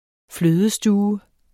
Udtale [ ˈfløːðəˌsduːvə ]